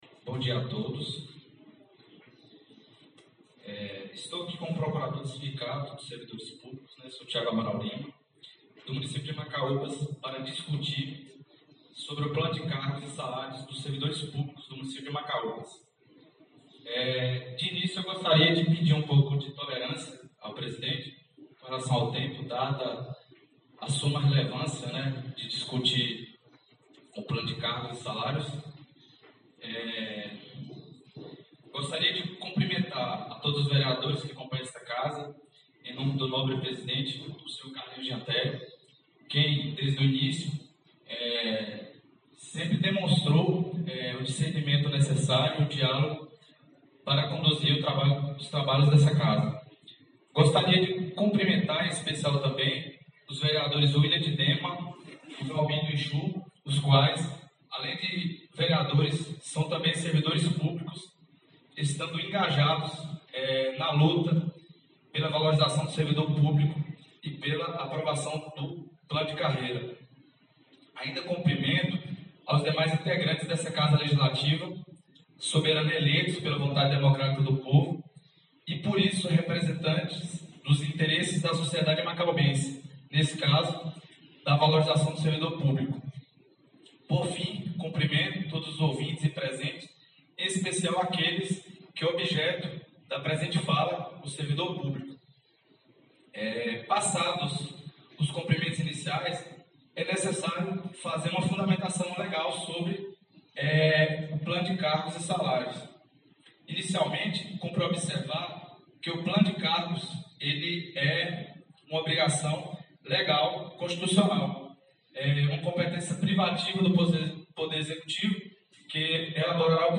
A 19ª Sessão Ordinária do Segundo Período Legislativo da Legislatura 2021-2024 da Câmara Municipal de Macaúbas foi realizada nesta quinta-feira, 04 de novembro de 2021, às 8h, no plenário da casa da cidadania.
usou a tribuna popular para falar sobre o novo Plano de Carreira dos Servidores Público de Macaúbas.